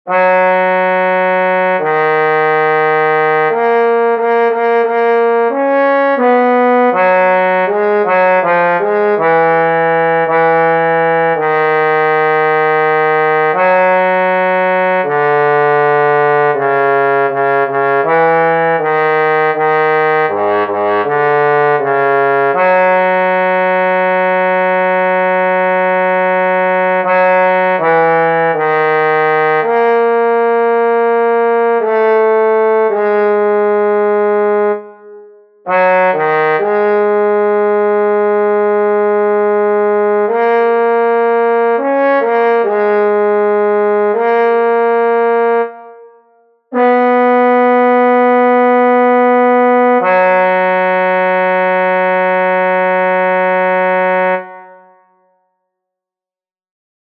Key written in: F# Major
Type: Barbershop